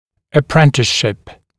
[ə’prentɪsʃɪp][э’прэнтисшип]стажировка; обучение под руководством более опытного специалиста